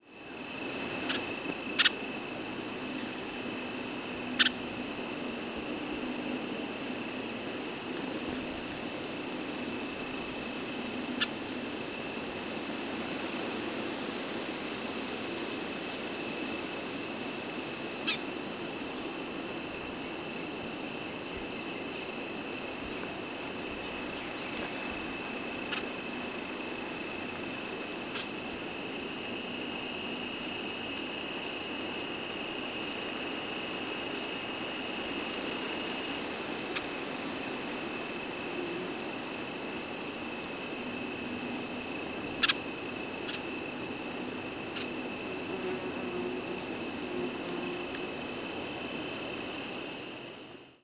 Ruby-crowned Kinglet Regulus calendula
Flight call description A husky "ji-dit" is given in short flights and may sometimes be used as a flight call.
Diurnal calling sequences:
Two birds foraging.
Bird in short flight.
Calls regularly while perched and in short diurnal flights.